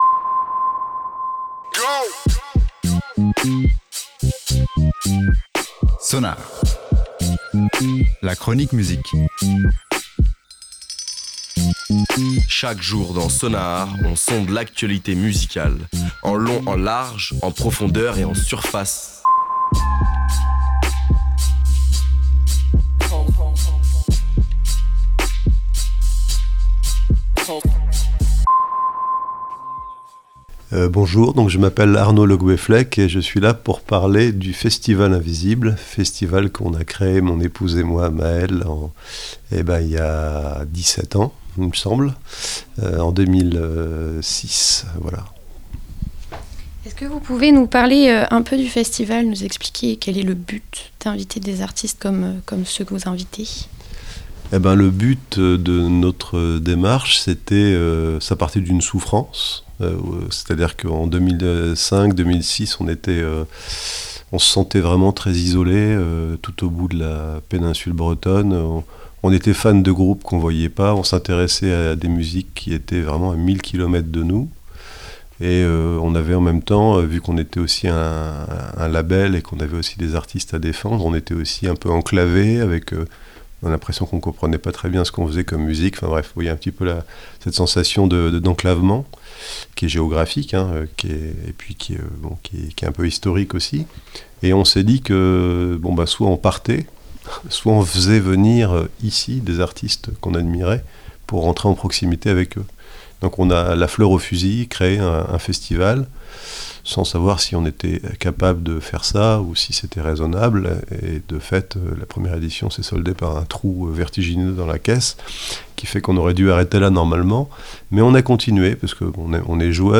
Interview de l’un des fondateurs de cet événement brestois